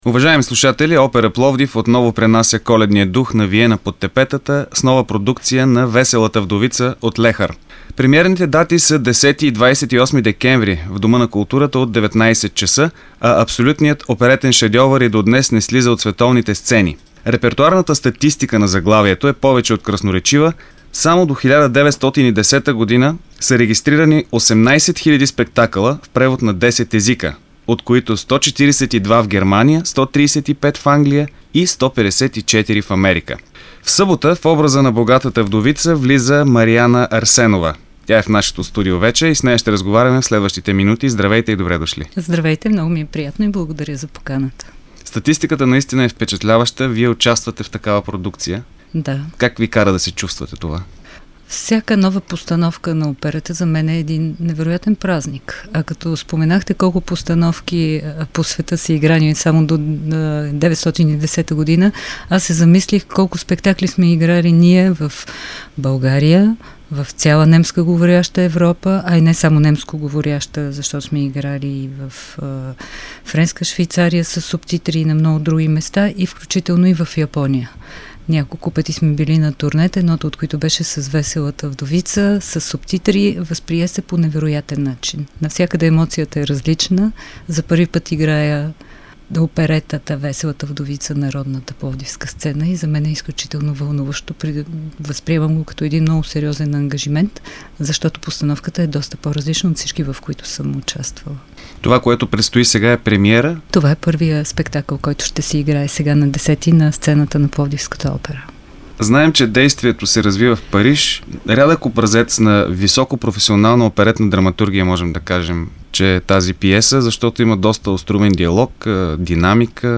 Регионална програма от Пловдив изтегли Опера Пловдив отново пренася коледния дух на Виена под тепетата с нова продукция на „ Веселата вдовица ” от Франц Лехар .